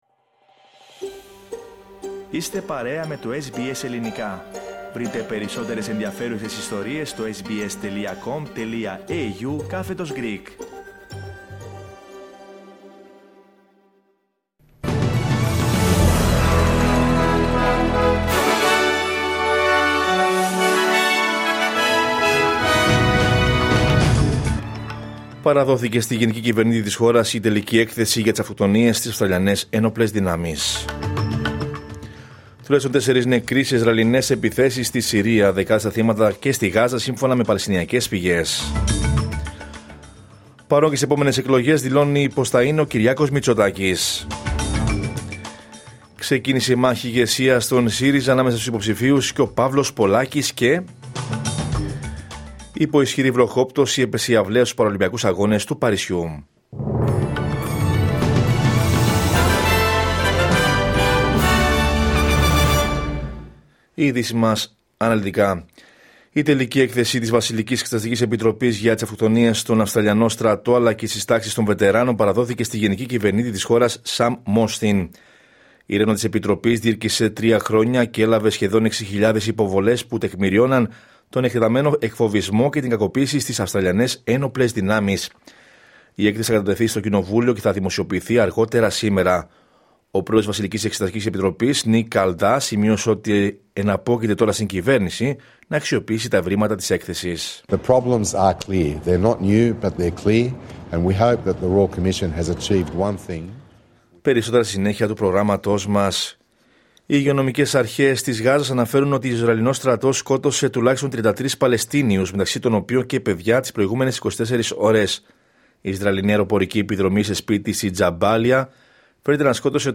Δελτίο Ειδήσεων Δευτέρα 9 Σεπτεμβρίου 2024